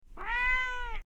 Cat Meowing #2 | TLIU Studios
Category: Animal Mood: Curious Editor's Choice